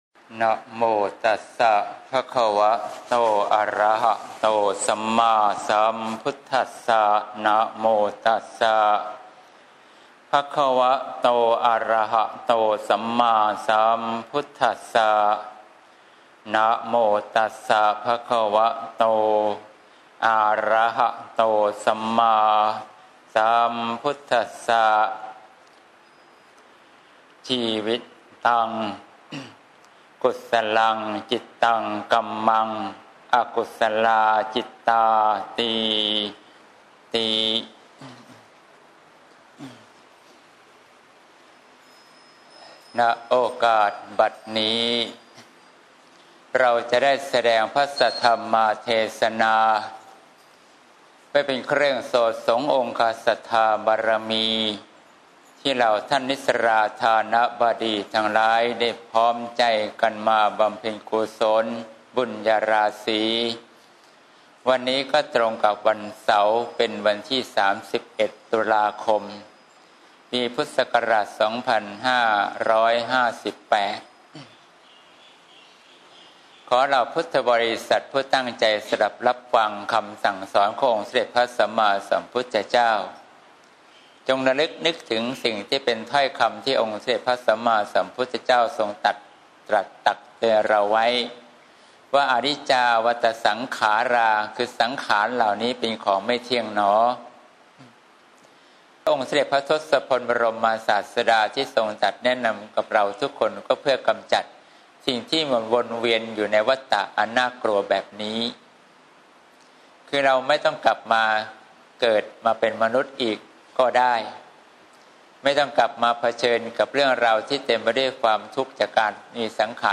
เทศน์ (เสียงธรรม ๑๑ ม.ค. ๖๘)